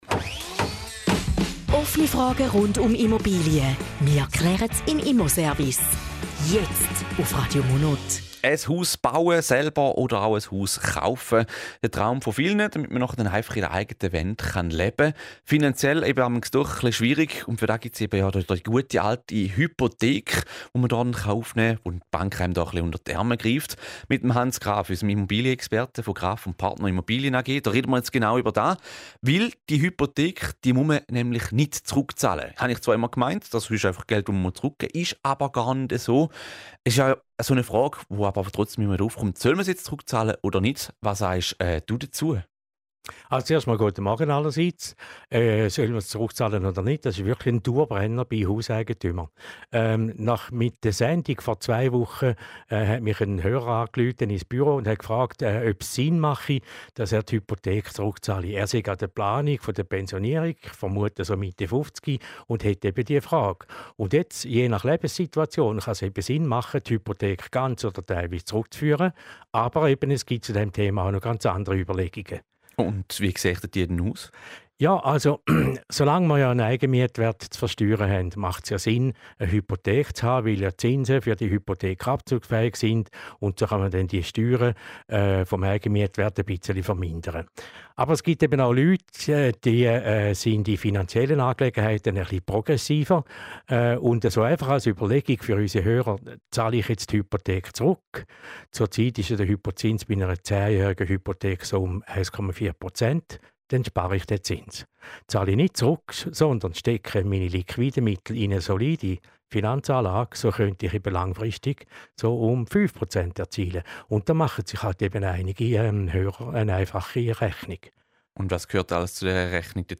Zusammenfassung des Interviews zum Thema "Hypothek zurückzahlen":